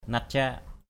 /nat-ca:ʔ/ (t.) quang đãng; sáng sủa = clair et vaste. clear, blue sky, unclouded; light, bright. harei ni langik natcak hr] n} lz{K qTcK hôm nay trời quang đãng. sky...